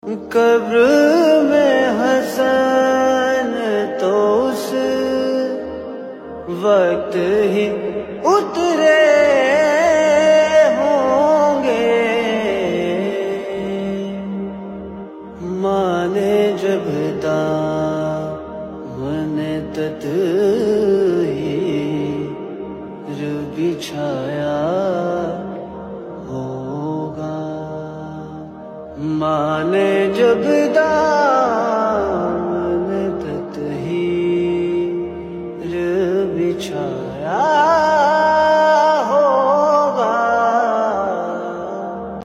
حسینیہ ہال موچی گیٹ لاہور مرکزی زیارت شبیہہ تابوت امام حسن مجتبیٰ علیہ السلام